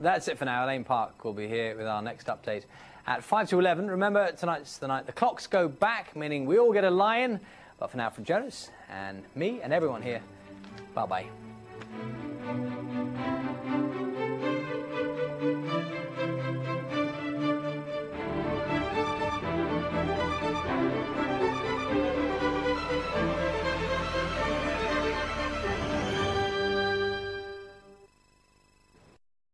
New look, new music and whats that?
Closing Titles